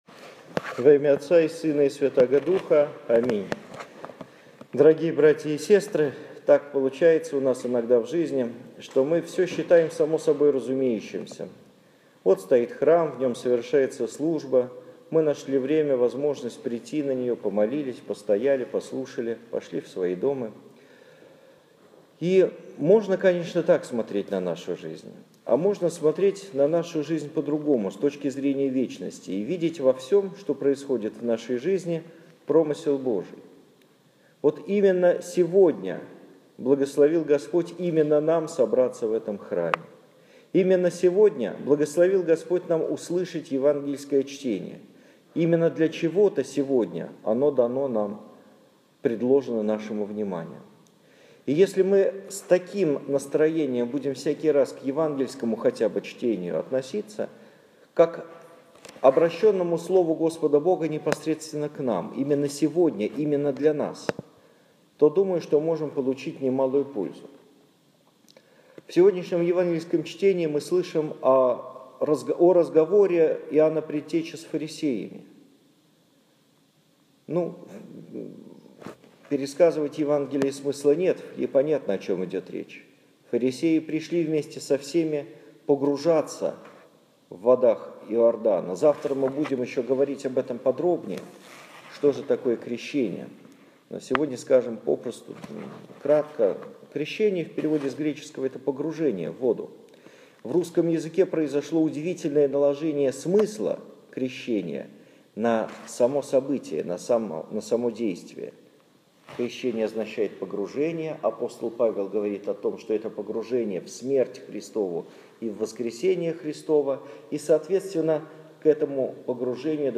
Моя проповедь 17 января 2015 года в нашем Петропавловском храме (Шуваловский парк, Парголово, СПб) после Евангелия на литургии.
Домой / Проповеди / Аудио-проповеди / 17 января 2015 года.